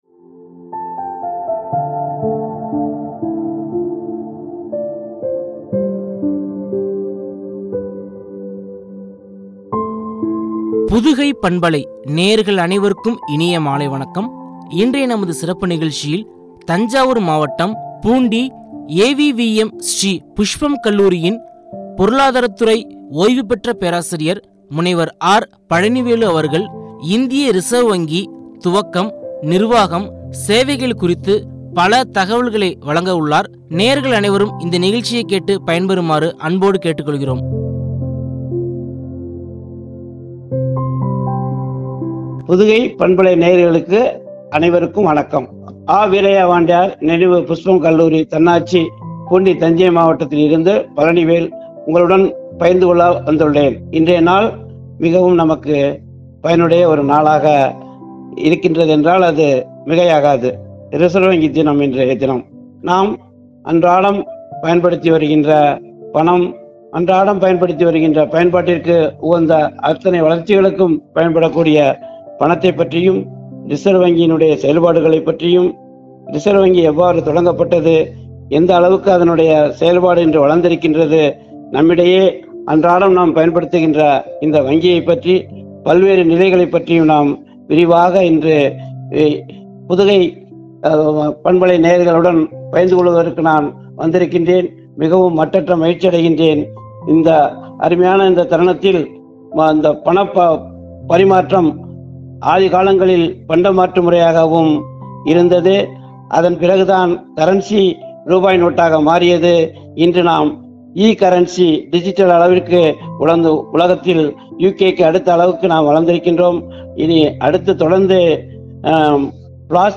சேவை” குறித்து வழங்கிய உரையாடல்.